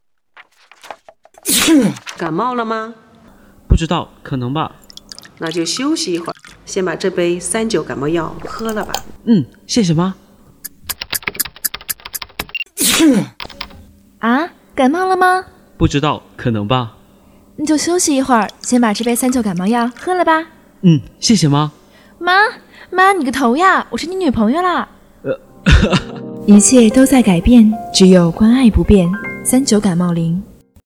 【广播作品】误会篇（第六届大广赛三等奖）-衡阳师范新闻与传播学院
广播类